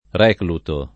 reclutare
recluto [ r $ kluto ]